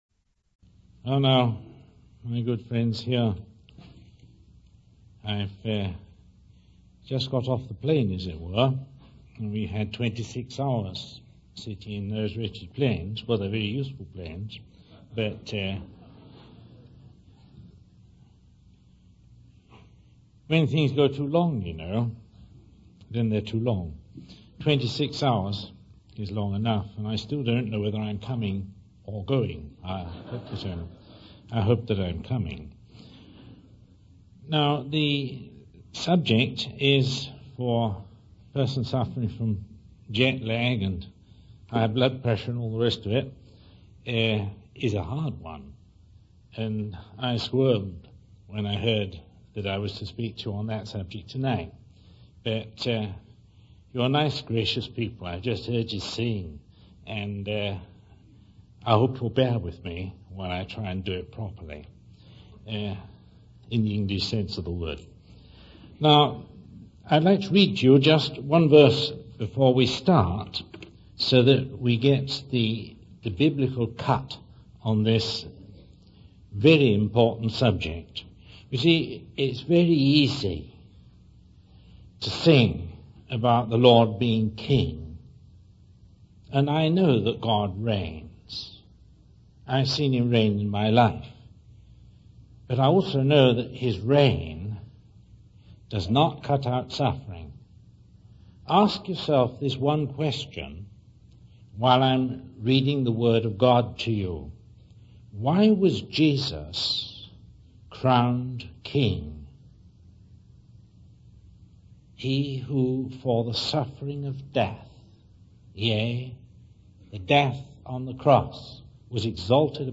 In this sermon, the speaker addresses the question of why God allows illness to exist in the world.